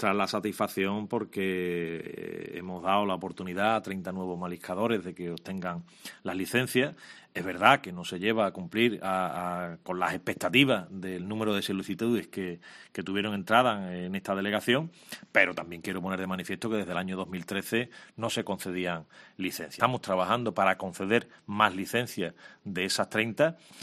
Álvaro Burgos, delegado de Pesca en Huelva